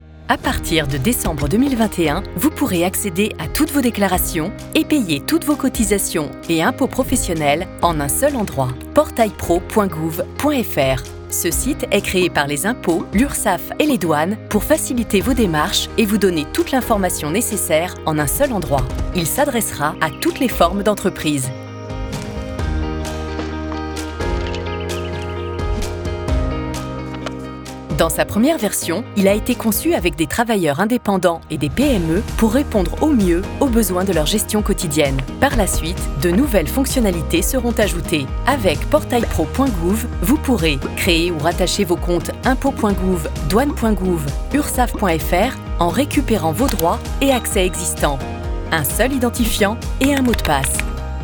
Institutionnel voix chaleureuse voix dynamique Voix chaleureuse Catégories / Types de Voix Extrait : Votre navigateur ne gère pas l'élément video .